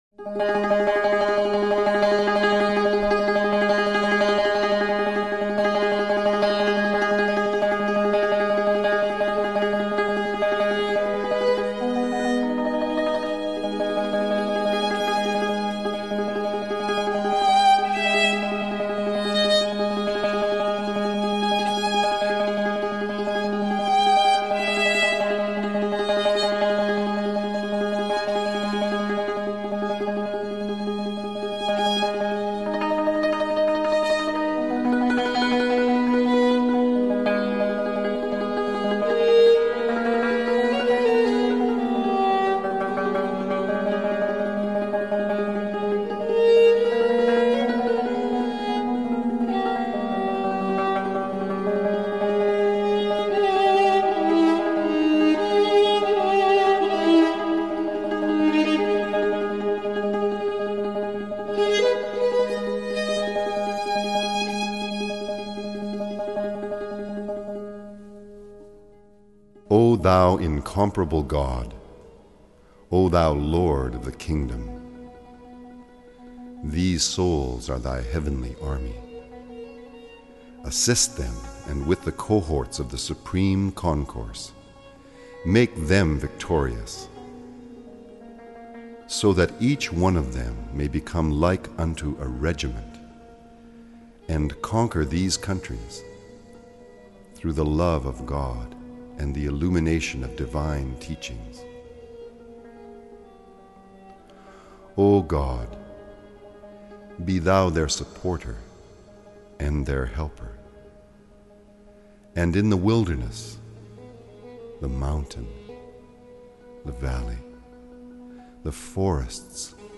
سرود - شماره 4 | تعالیم و عقاید آئین بهائی